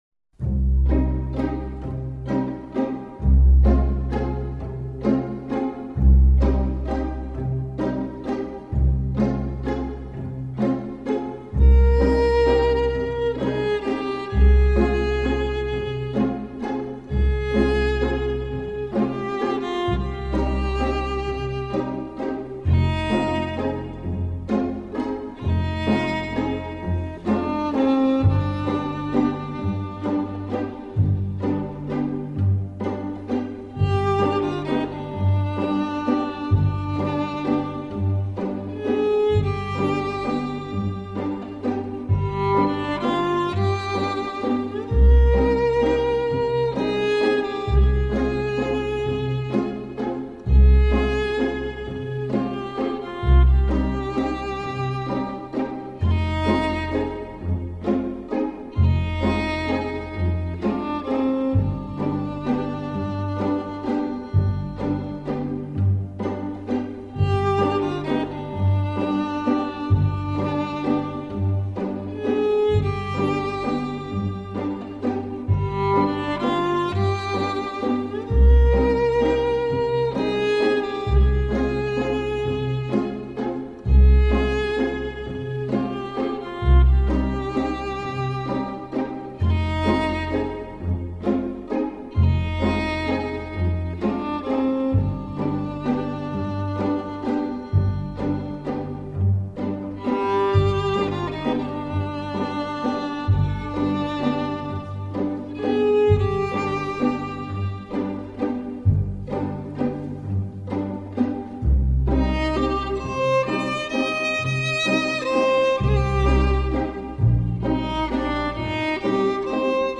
موسیقی بی کلام پیانو